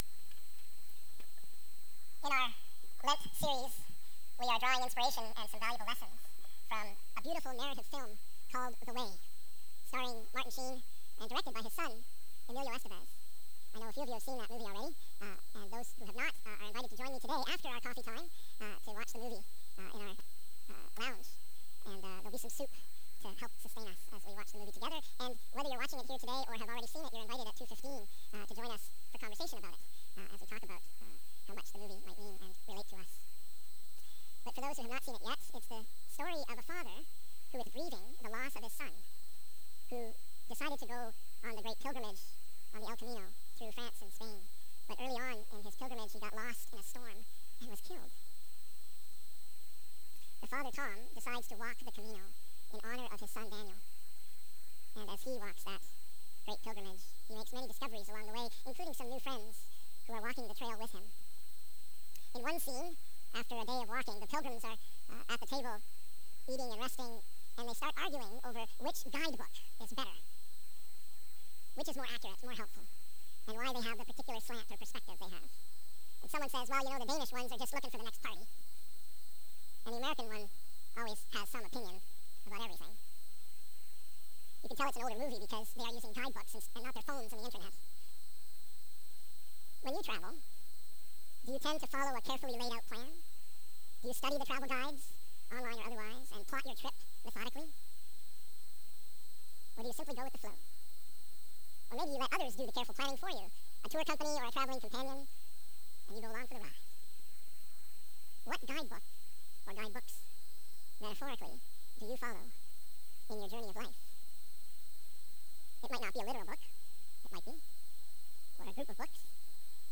March 8 2026 Sermon - The Way Around